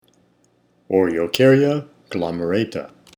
Pronunciation/Pronunciación:
O-re-o-cár-ya  glo-me-rà-ta